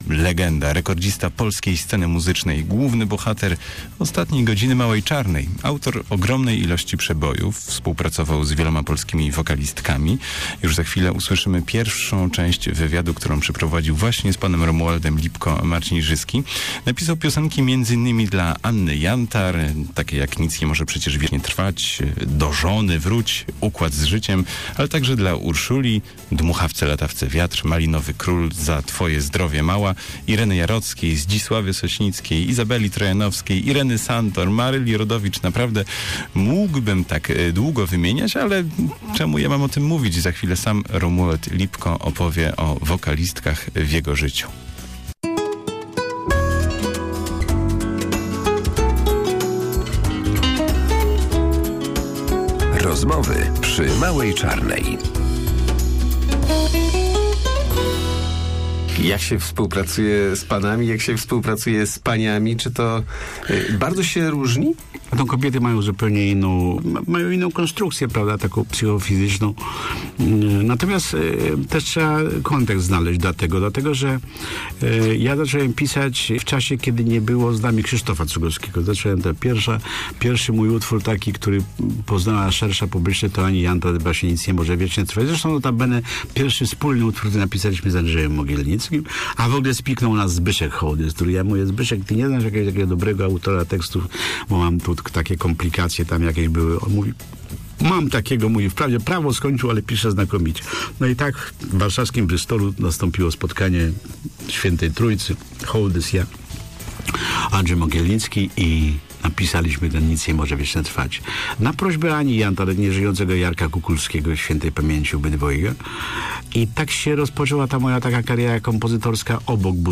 Gościem Małej Czarnej był Romuald Lipko.